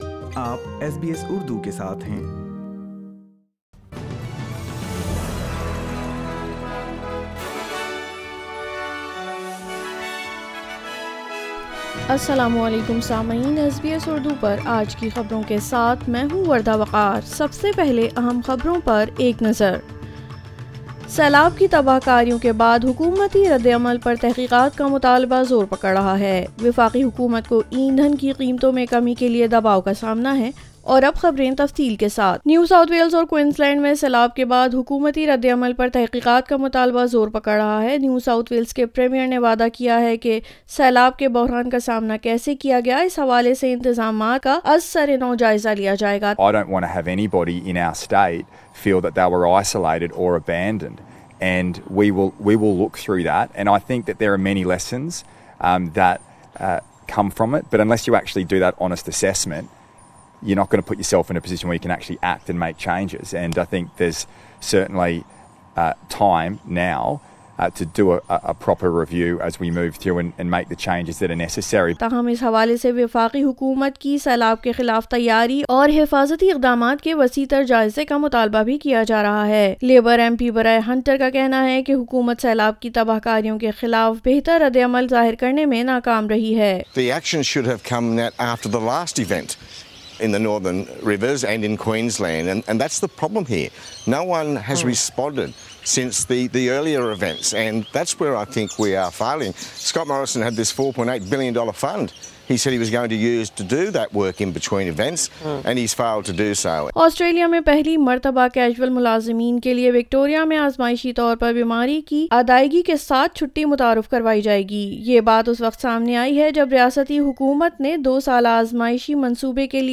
SBS Urdu News 14 March 2022